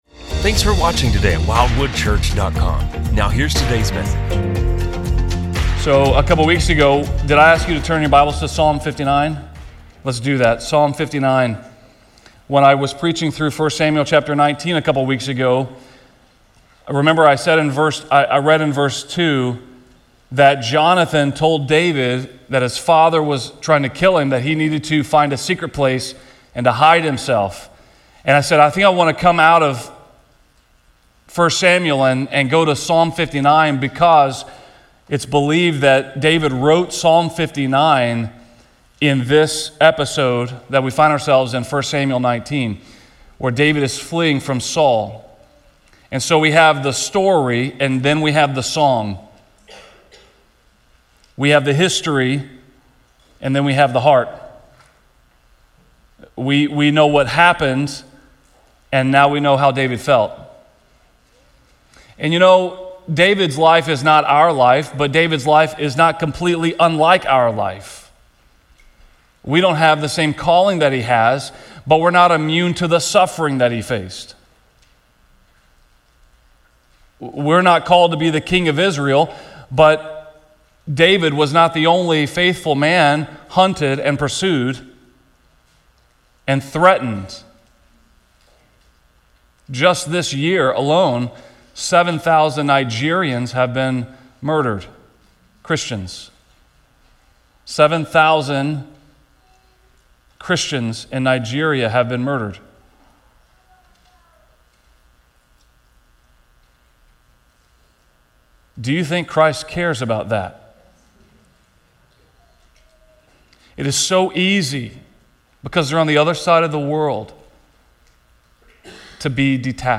A message from the series "The Life of David."